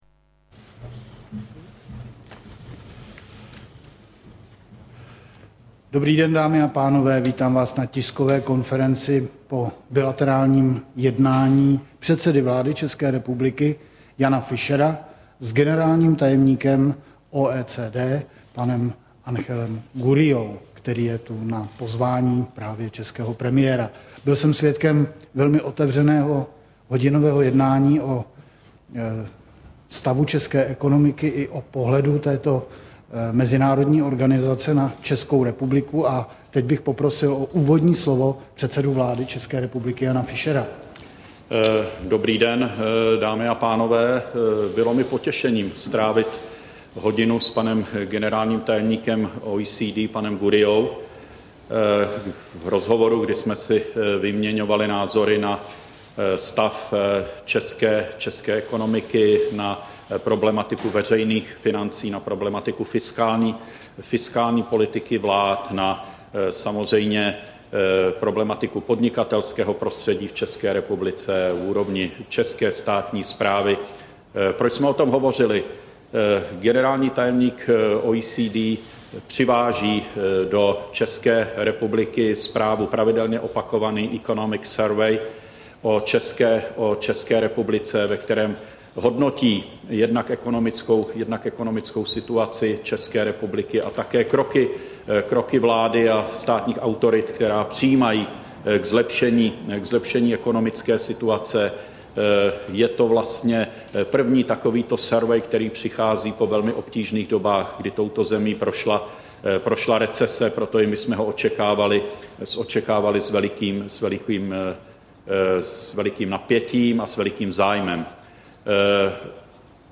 Tiskový brífink premiéra Jana Fischera a generálního tajemníka OECD Ángela Gurríi - česká verze, 6.4. 2010